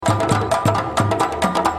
LOOPS GRATUITS DE RYTHMES AFRICAINS
Rythme Africain - Djembes 54